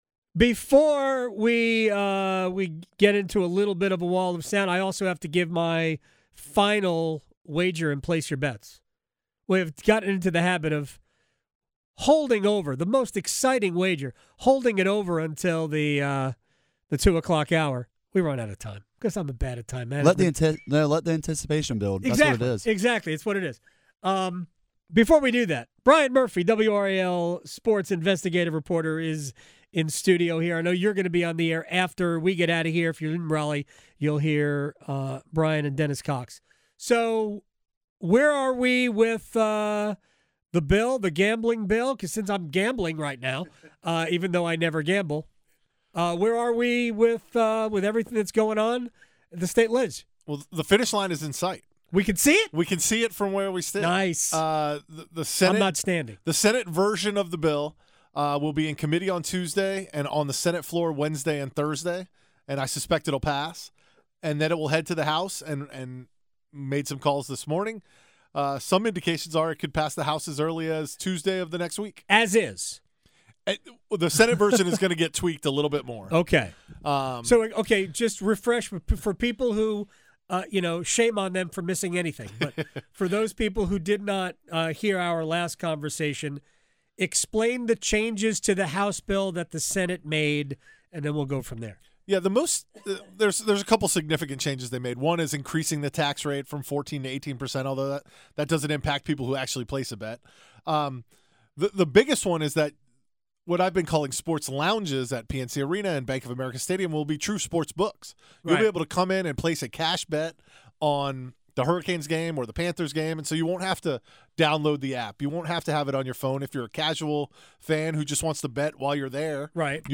The Truth Network Radio